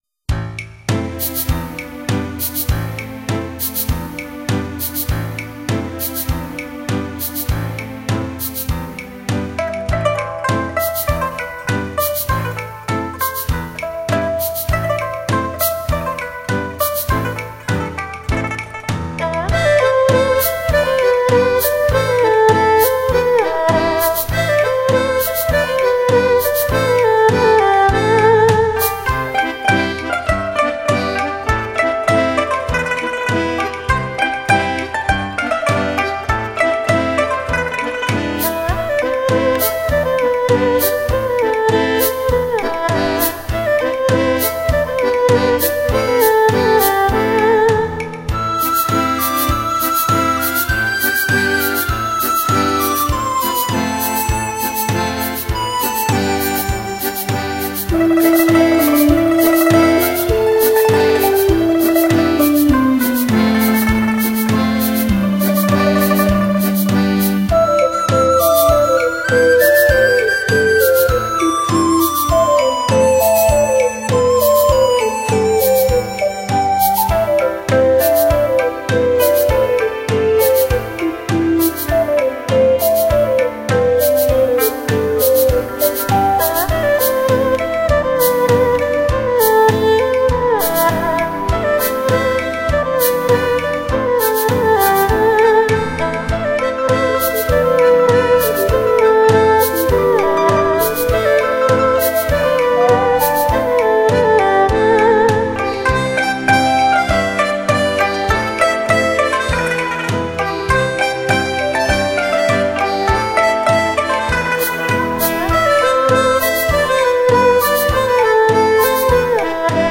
崭新风格的新体验，灵气迫人，悠雅怡然的，清新民乐精品。
碟中音色轻柔细腻、圆润质朴、深情委婉、旋律优美。
被乐手们演奏得激情十足，使得整套系列充满强烈的时代感和现代感。